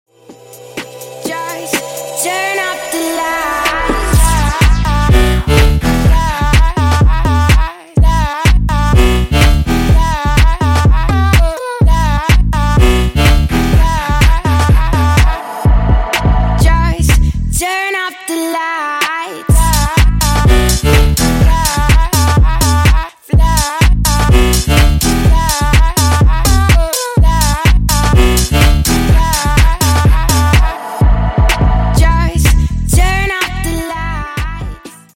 Клубные Рингтоны » # Громкие Рингтоны С Басами
Танцевальные Рингтоны